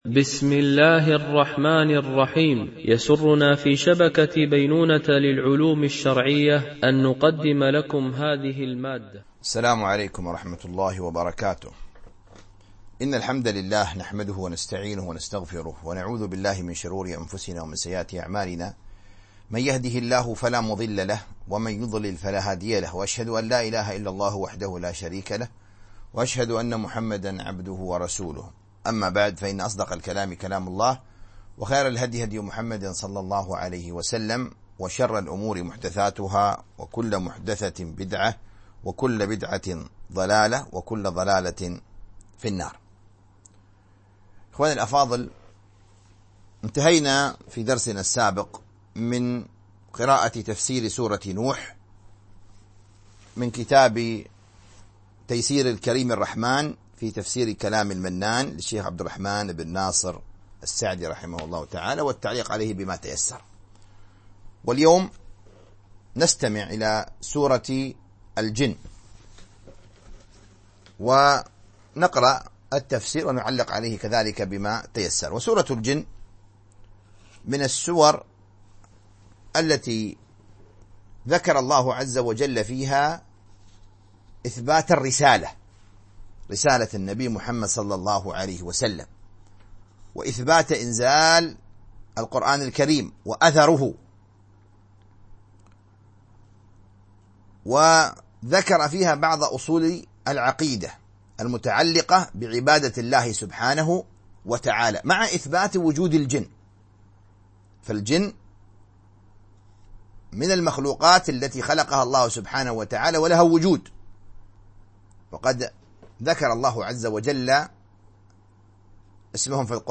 تفسير جزء تبارك - الدرس 12 ( تفسير سورة الجن )